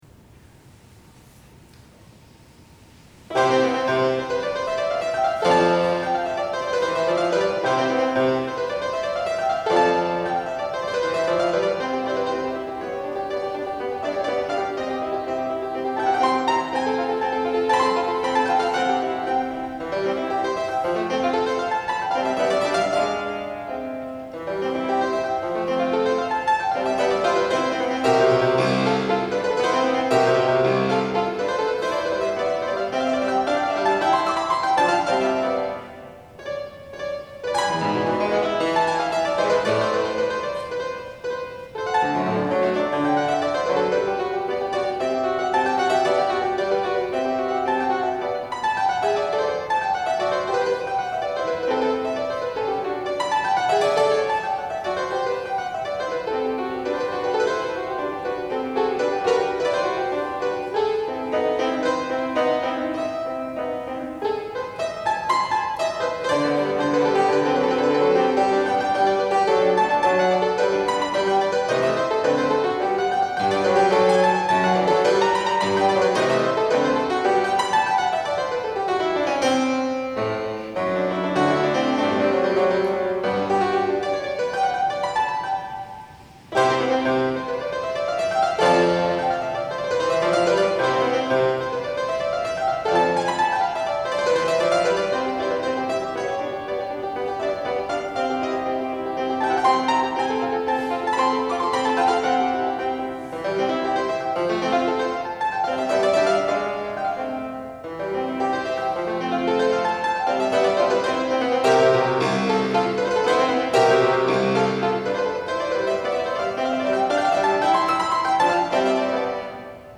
Viennese action fortepiano
Live recording